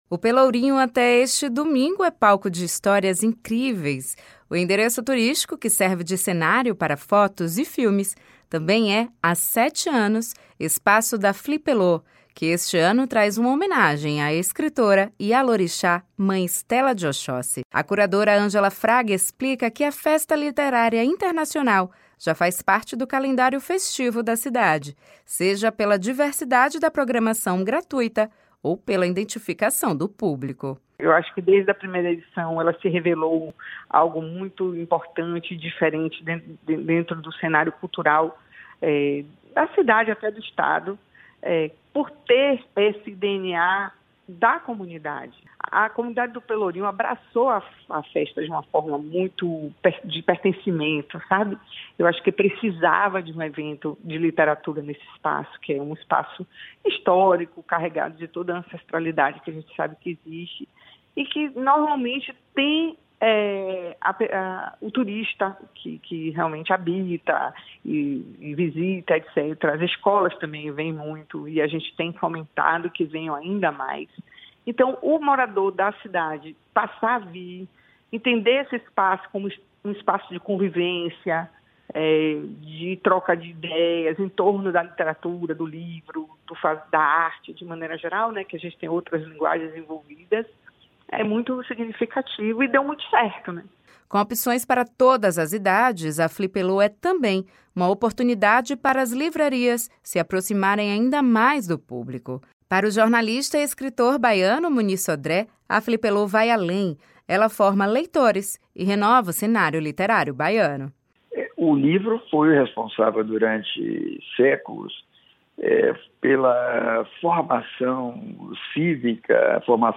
Para o jornalista e escritor baiano Muniz Sodré, a Flipelô vai além: ela forma leitores e renova o cenário literário baiano.